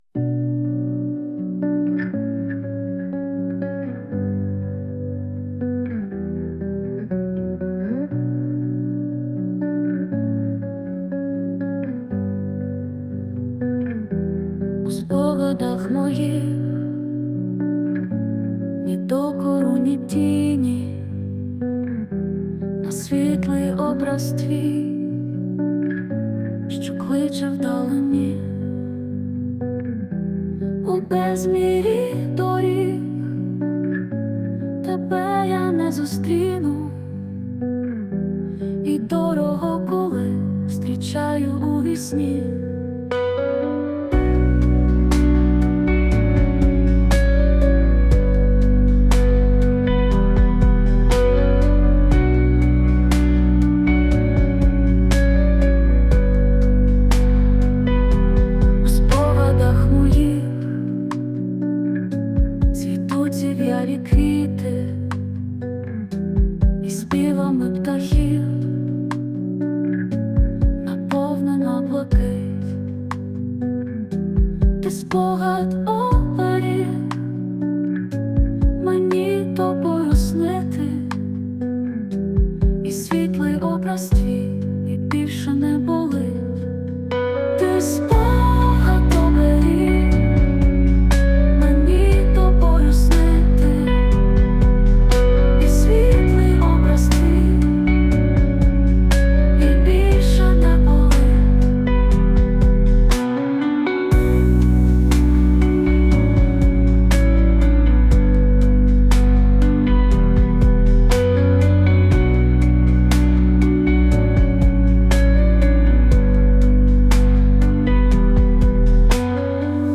Музика ШІ
СТИЛЬОВІ ЖАНРИ: Ліричний
ВИД ТВОРУ: Пісня